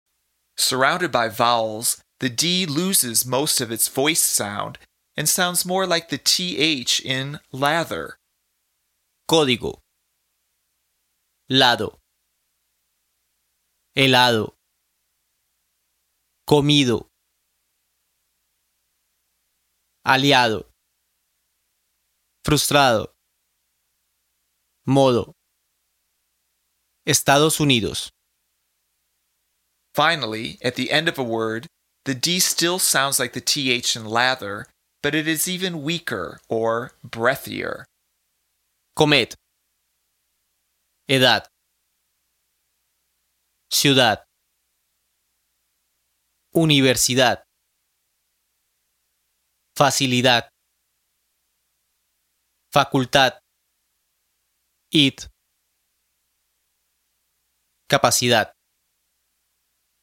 Surrounded by vowels, the “d” loses most of its “voiced” sound, and sounds more like the “th”
Finally, at the end of a word, the “d” still sounds like the “th” in “lather,” but it is even weaker